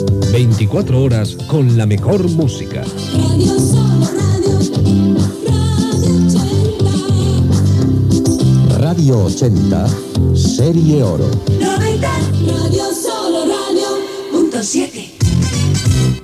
Indicatiu de la cadena radiofònica.